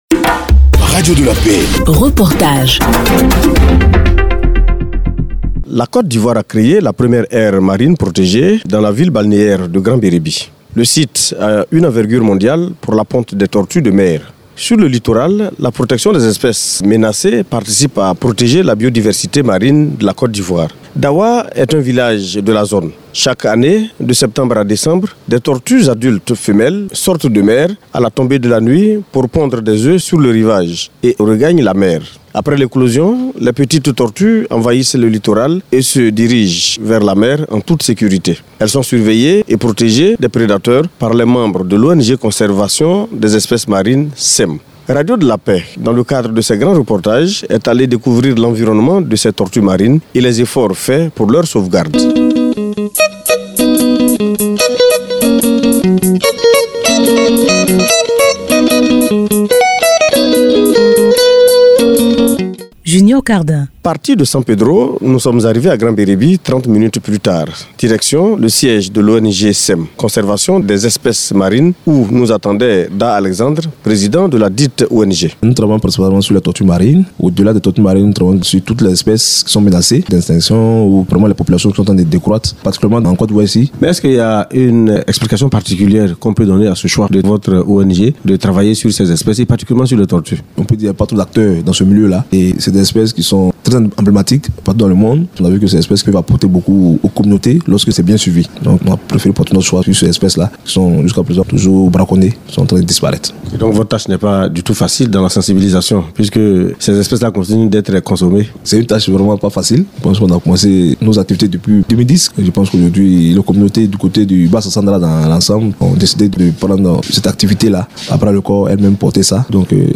Reportage -Sauvegarde des tortues marines à Dawa – Grand Bereby - Site Officiel de Radio de la Paix